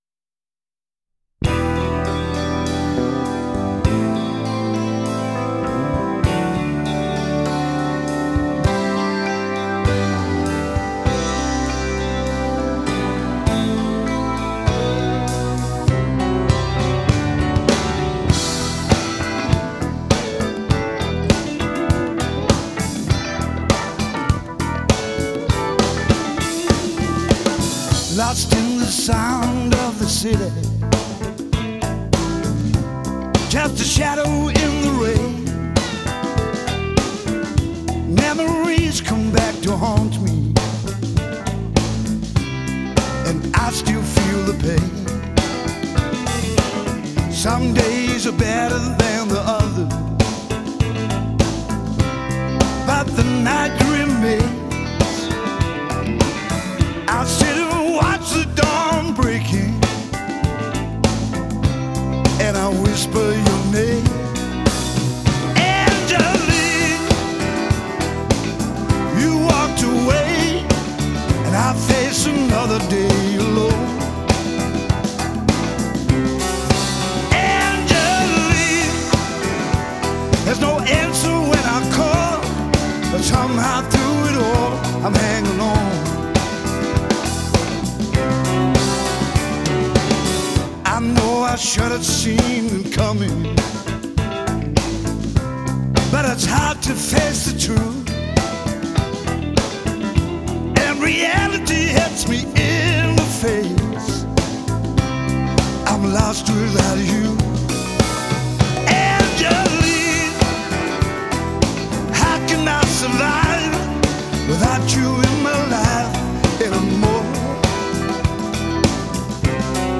характерным хриплым вокалом